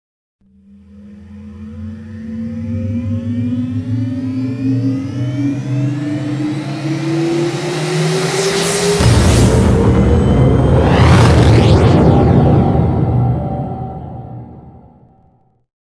OtherLaunch3.wav